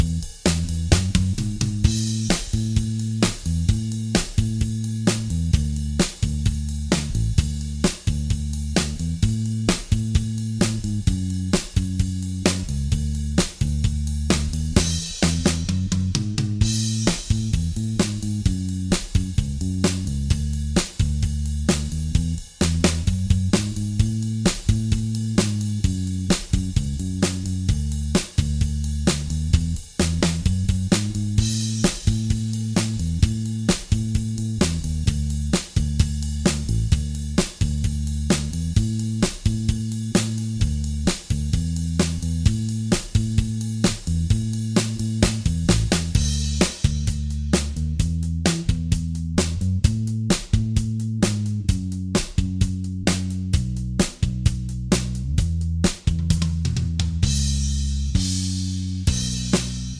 Minus Main Guitars For Guitarists 3:31 Buy £1.50